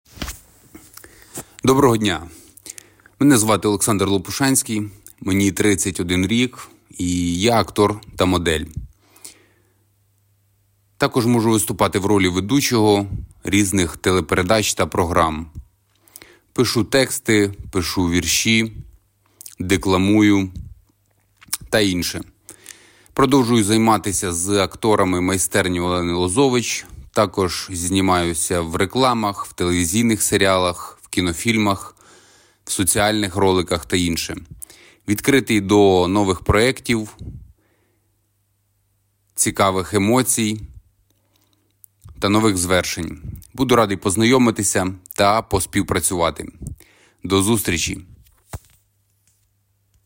Чоловіча
Баритон